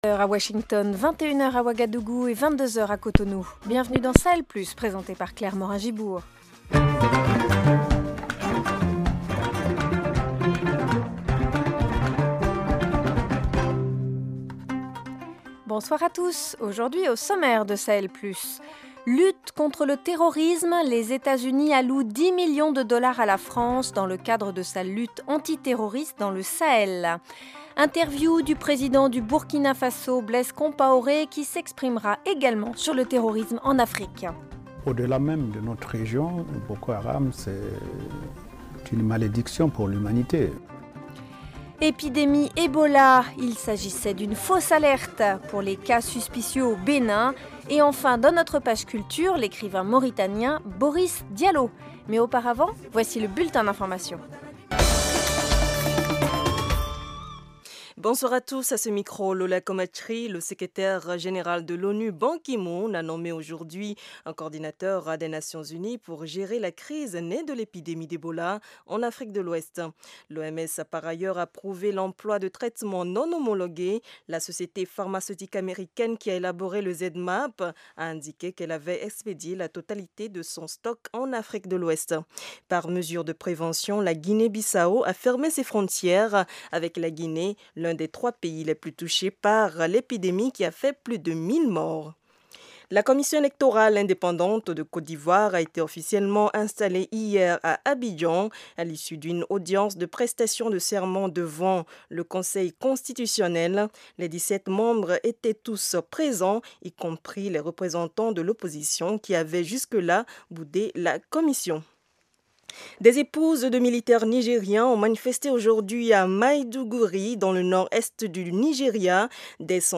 Au programme : les Etats-Unis allouent 10 millions de dollars à la France pour la lutte anti-terroriste dans le Sahel. Interview du président du Burkina Faso Blaise Compaoré sur le terrorisme en Afrique. Epidémie Ebola : fausse alerte au Bénin.